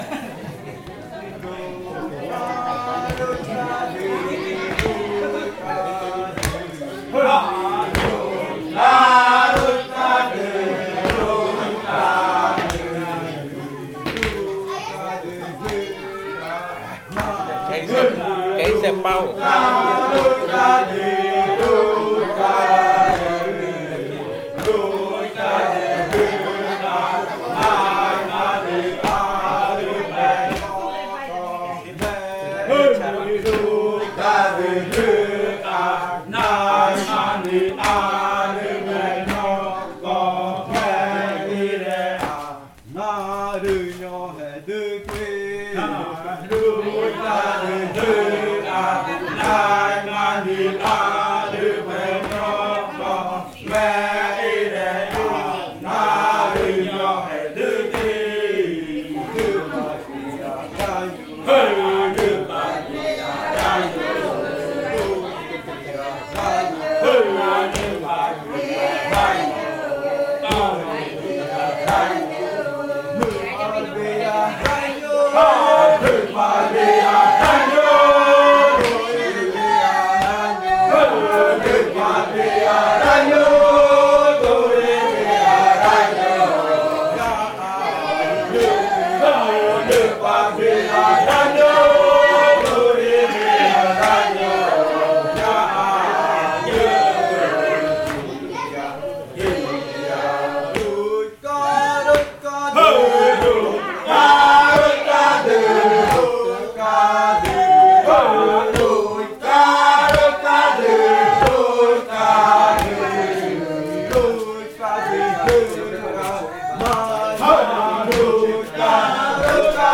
Canto de la variante de muruikɨ
Leticia, Amazonas
con el grupo de cantores bailando